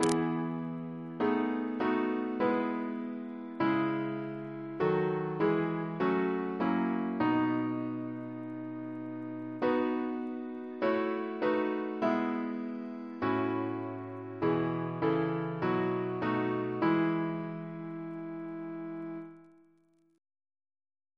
Double chant in E Composer: Edmund T. Chipp (1823-1886) Reference psalters: ACB: 175; ACP: 155; CWP: 96; OCB: 79 356; RSCM: 32